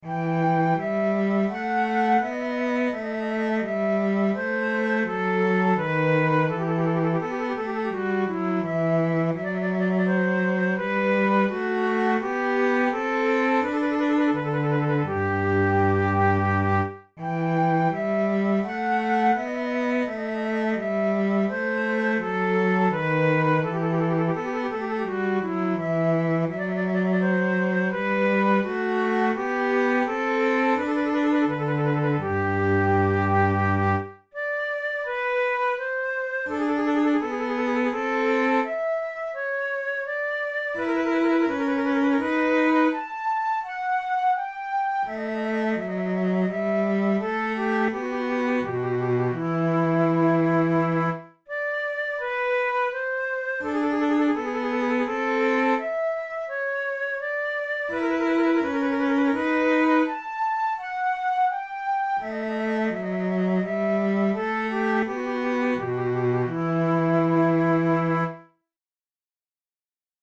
InstrumentationFlute and bass instrument
KeyE minor
Time signature3/8
Tempo84 BPM
Baroque, Sonatas, Written for Flute